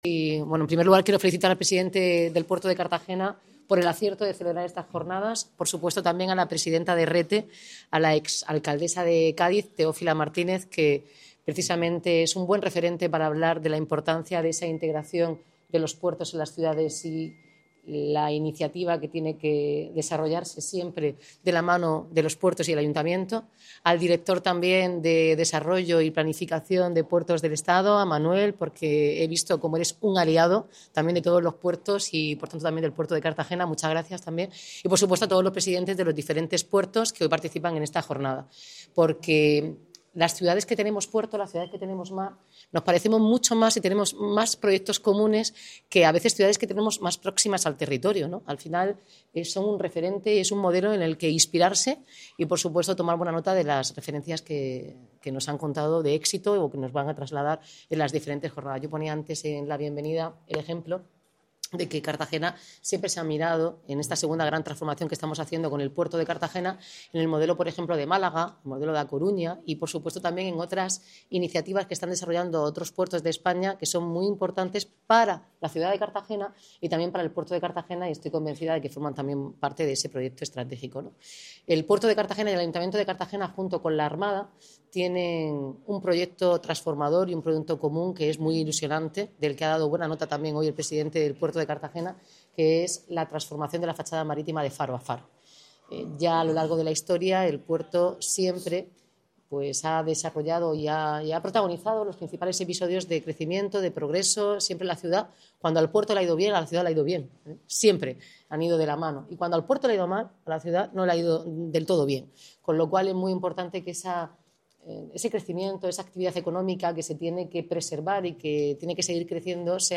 Enlace a Declaraciones de Noelia Arroyo, Pedro Pablo Hernández y Teófila Martínez en las Jornadas del Puerto